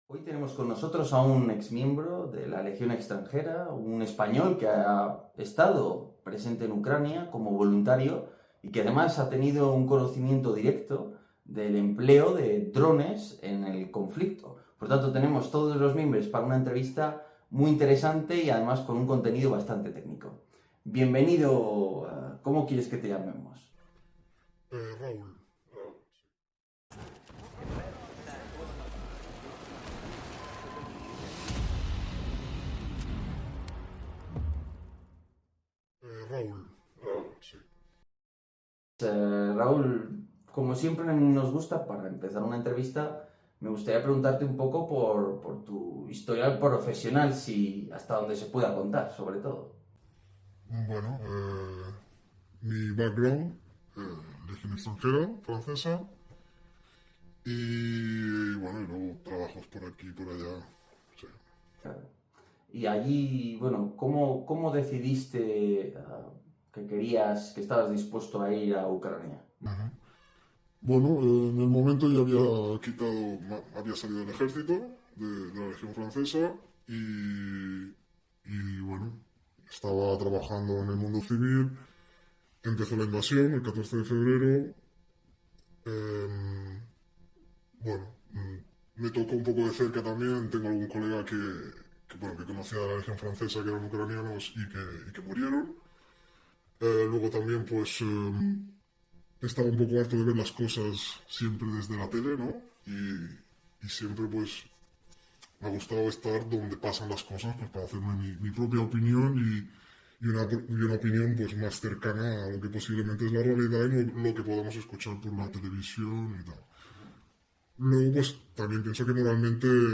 Un español enseña a sobrevivir a los drones en Ucrania | Entrevista reveladora